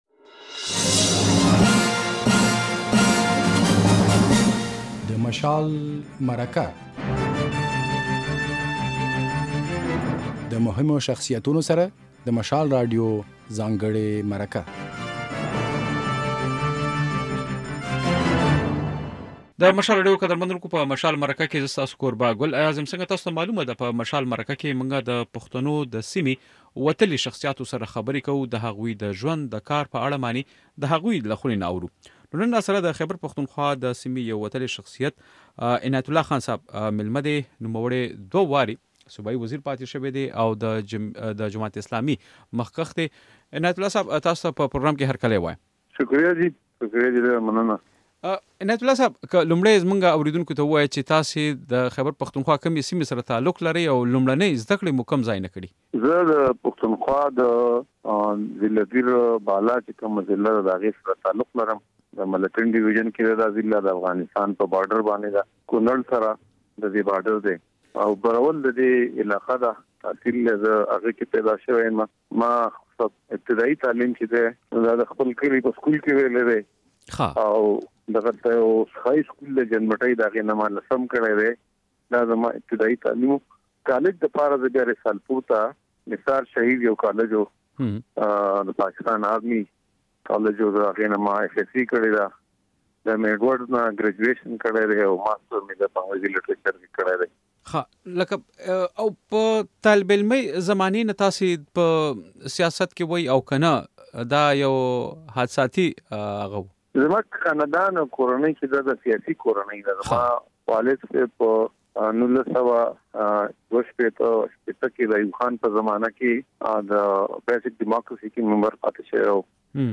په مشال مرکه کې مو د خيبر پښتونخوا پخوانی صوبايي وزير عنايت الله خان مېلمه کړی دی.
دی وايي، ځوانان باید ځان ته په ژوند کې اهداف وټاکي او په سیاسي بهیر کې هم فعاله ونډه ولري. بشپړه مرکه د غږ په ځای کې واورئ.